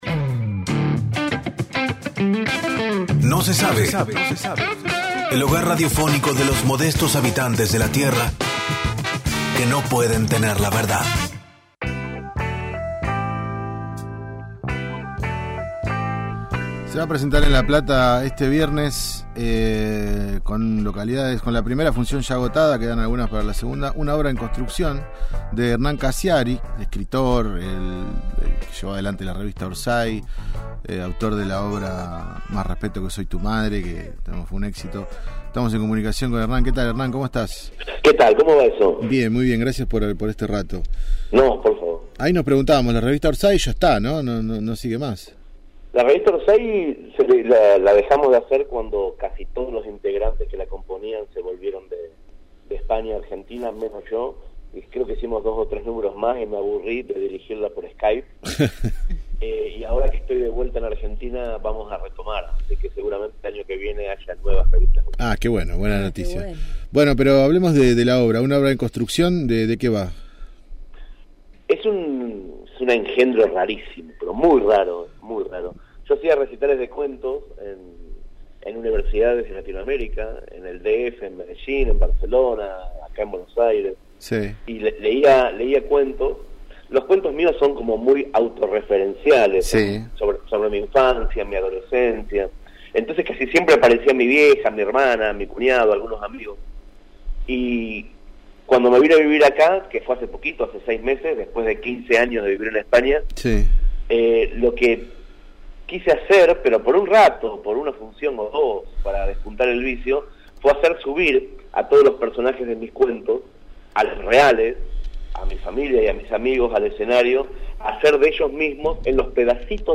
El periodista y escritor Hernán Casciari dialogó con el equipo de «No se sabe» sobre las dos funciones que realizará este viernes 16 de septiembre en el Teatro La Nonna de «Una obra en construcción», a las 20.45  y a las 22.45.